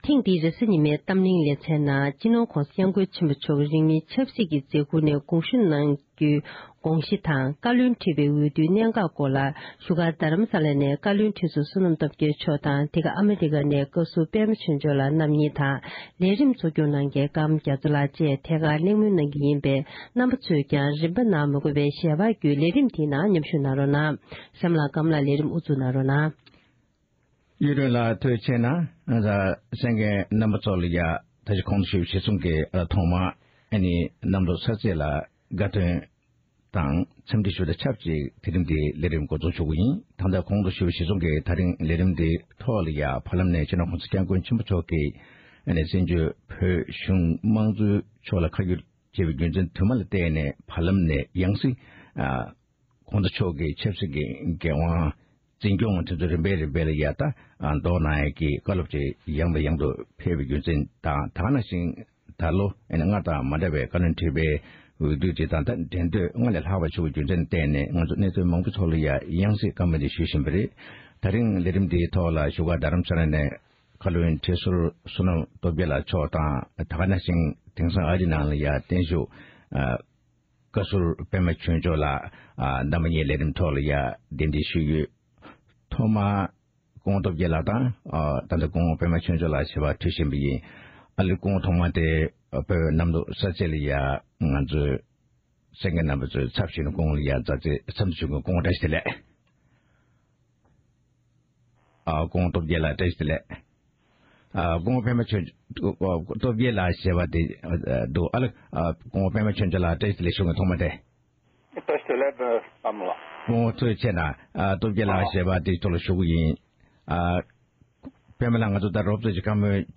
ཐེངས་འདིའི་རེས་གཟའ་ཉི་མའི་གཏམ་གླེང་གི་ལེ་ཚན་ནང་།